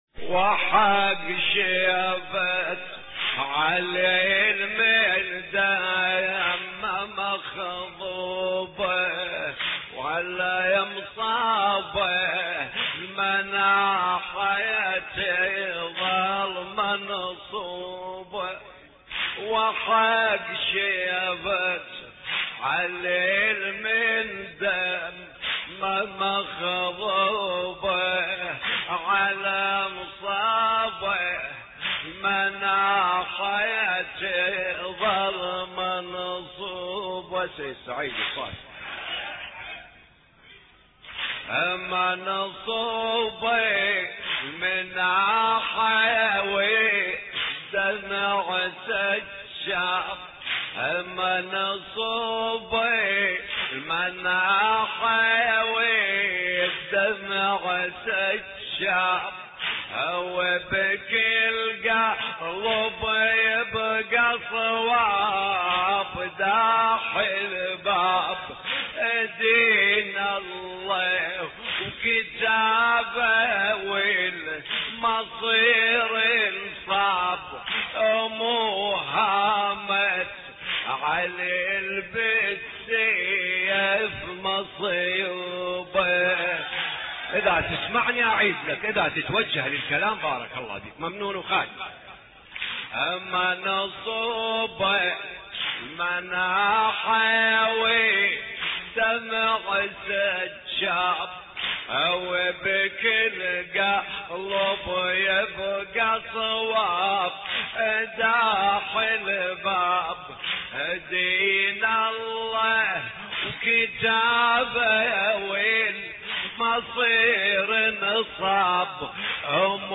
مراثي الامام علي (ع)